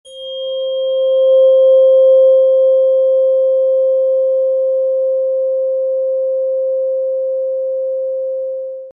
For the nights your brain won’t shut off: 528Hz 🌙 Just 9 seconds to clear the noise.